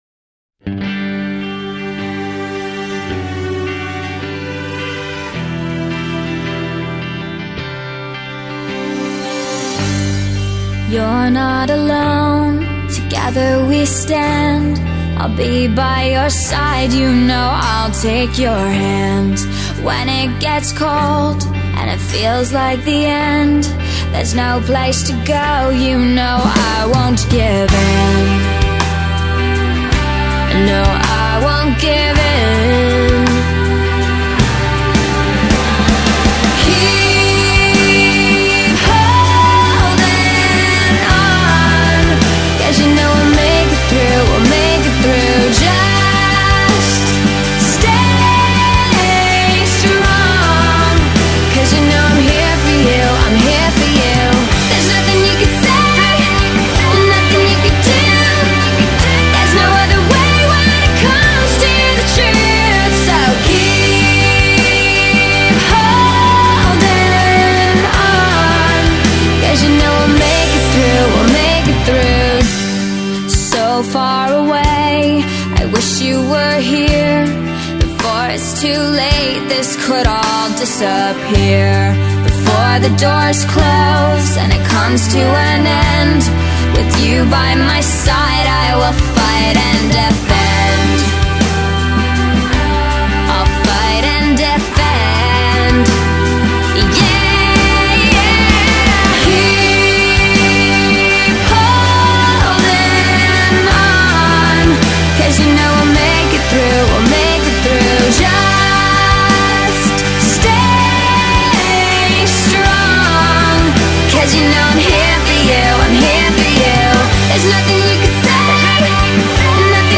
that is very good slow number